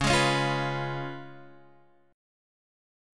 Db7 chord